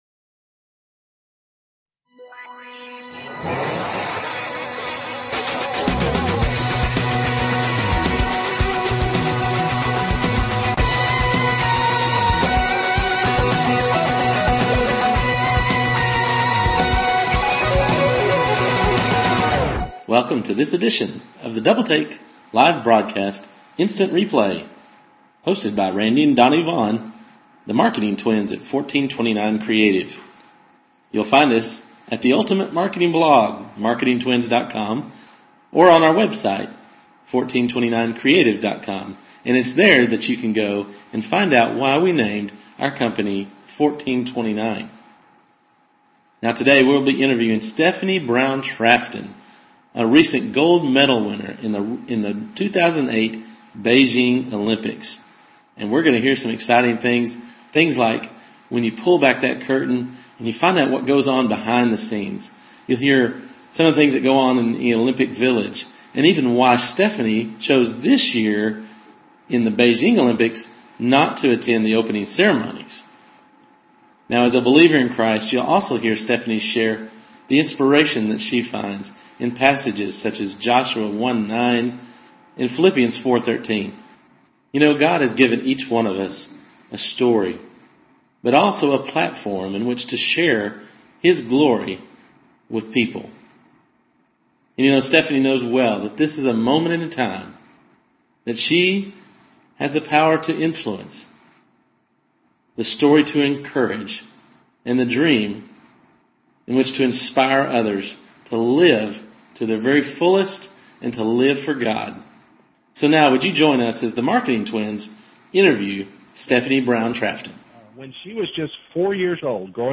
ARCHIVE: Interview with Stephanie Brown Trafton, Gold Medalist